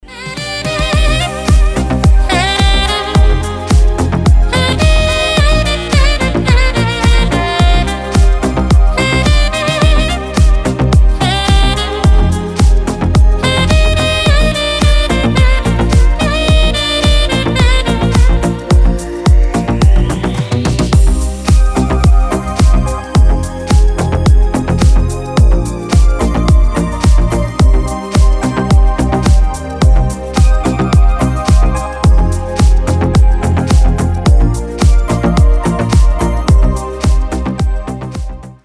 Рингтоны без слов
Романтические рингтоны
Саксофон
Deep house
Инструментальные